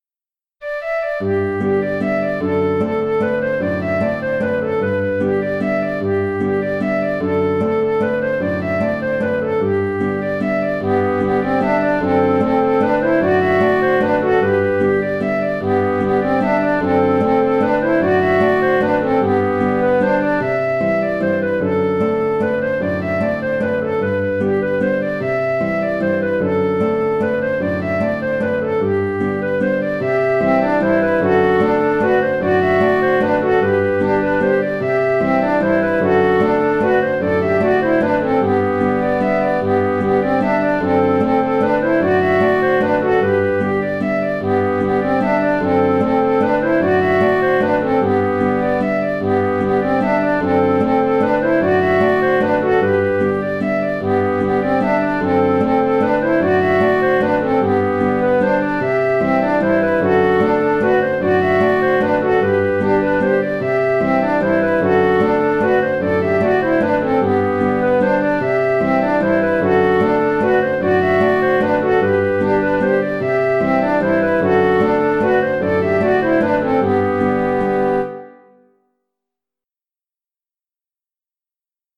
Mazurka de Servant (Mazurka) - Musique folk
Je ne connais pas l’origine de cette mazurka simple et agréable.
Le contrechant est aussi très accessible..